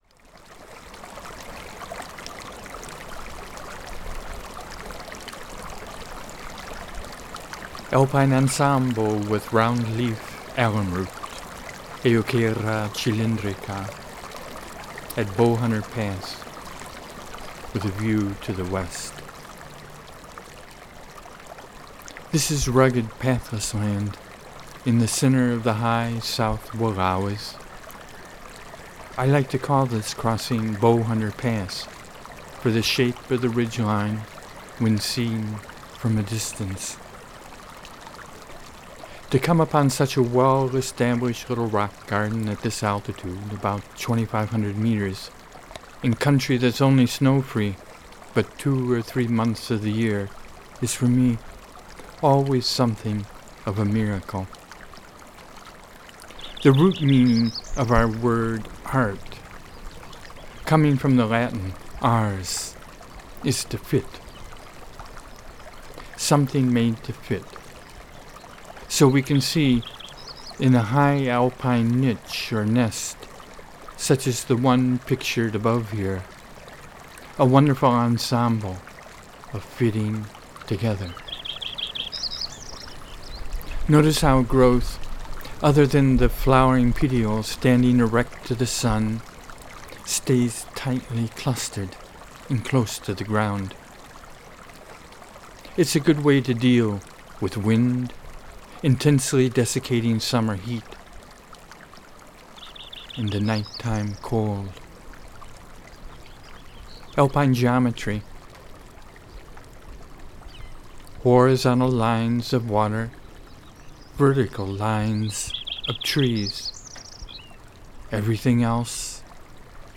MUSIC in the coda is from my THE MAGIC BOX,
the solo marimba piece, NIGHT PHANTOM score pdf |